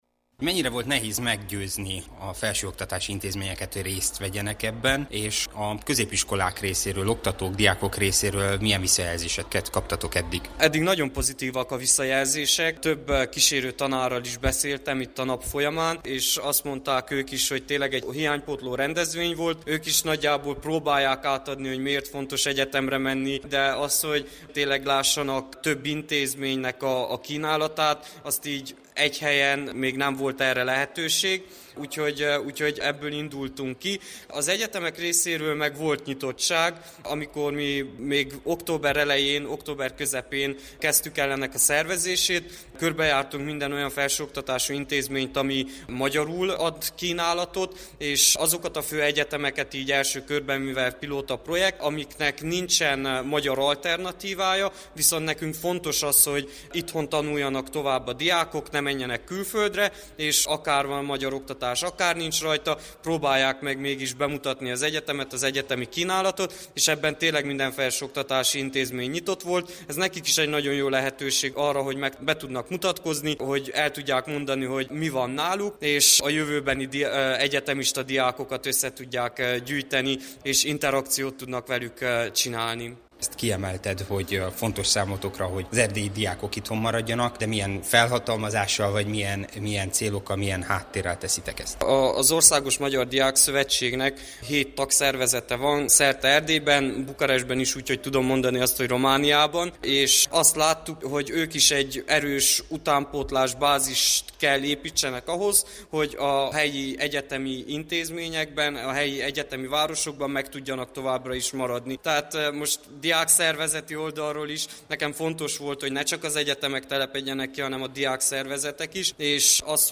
helyszíni riportja.